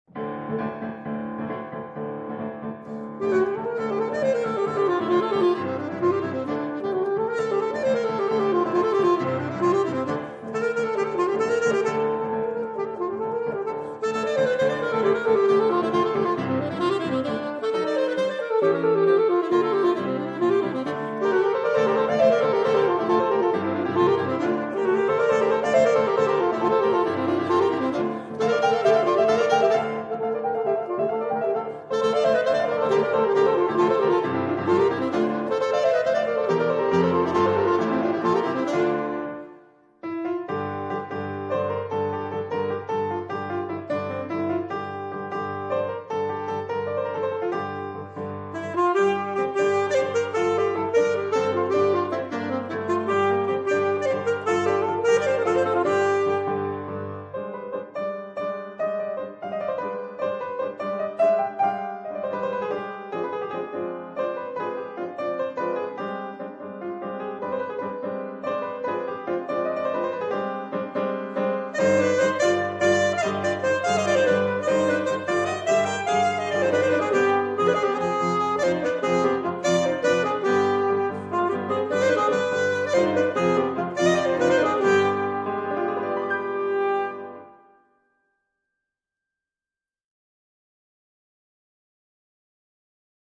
saxophone
piano
III. Valse